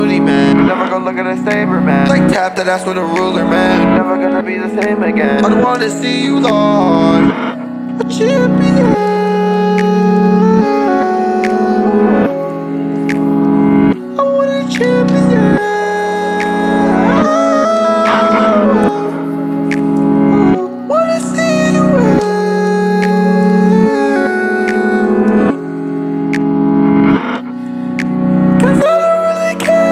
Жанр: Иностранный рэп и хип-хоп / Нью-эйдж / Рэп и хип-хоп